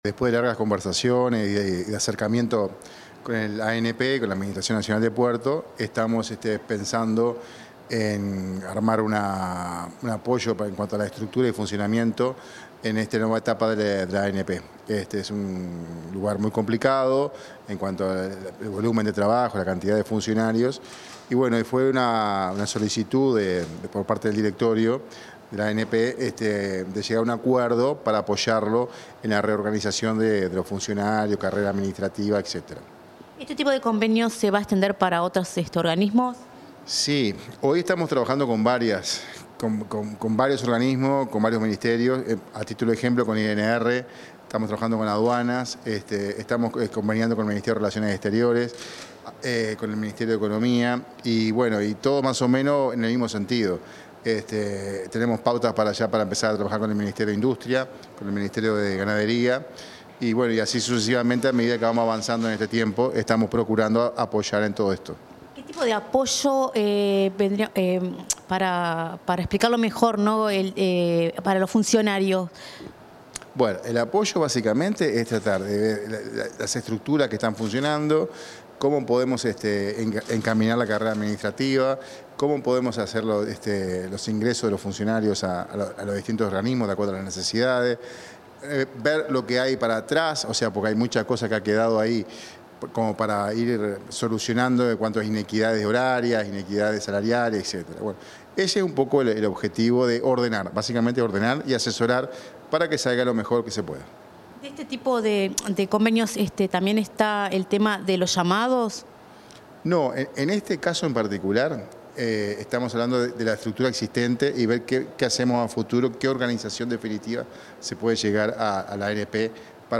Declaraciones del director de la ONSC, Sergio Pérez
Declaraciones del director de la ONSC, Sergio Pérez 14/08/2025 Compartir Facebook X Copiar enlace WhatsApp LinkedIn En oportunidad de la firma de un convenio marco entre la Oficina Nacional de Servicio Civil (ONSC) y la Administración Nacional de Puertos, el director de la ONSC dialogó con la prensa.